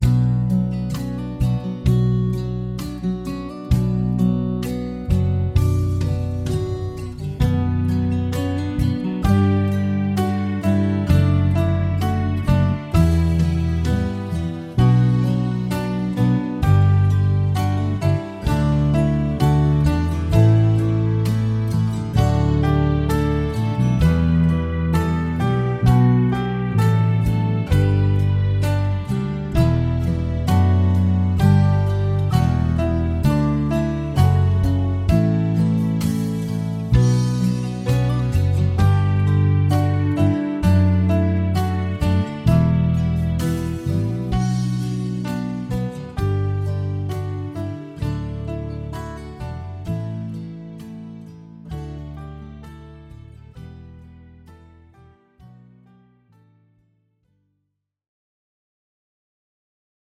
Latviešu tautas dziesma flautai Play-along.